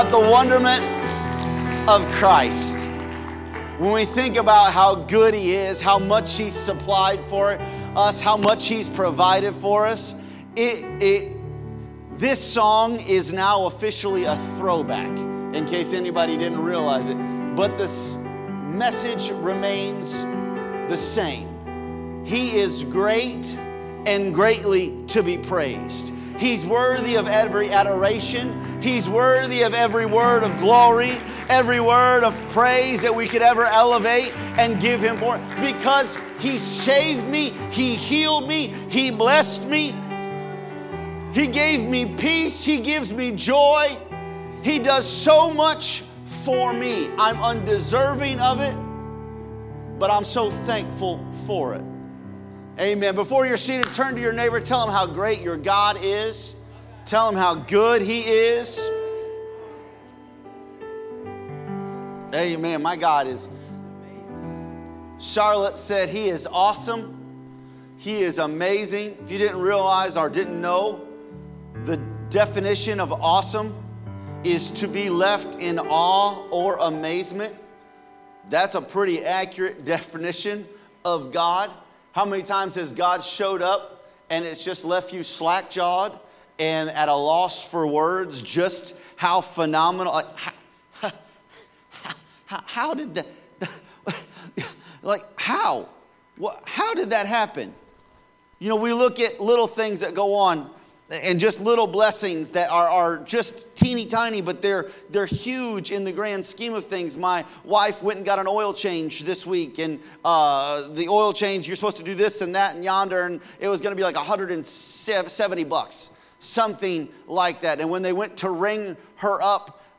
Sunday Service The Great I Am